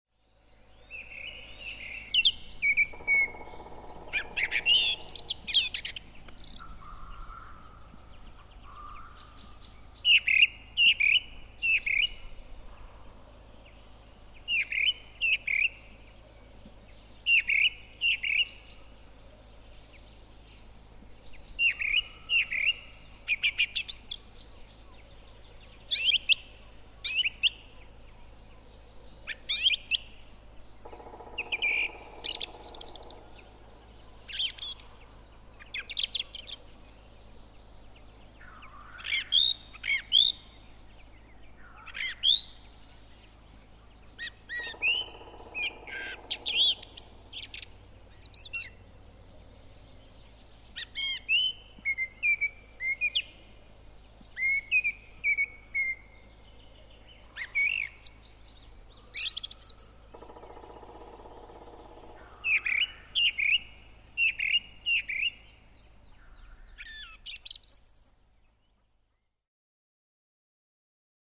101. LAULURASTAS (taltrast)
Äänet: Laulu kuuluvaa ja rauhallista, toistaa samaa aihetta 2–4 kertaa. Kutsuääni terävä ”tsik”.
laulurastas-copyright-birdlife.mp3